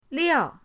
liao4.mp3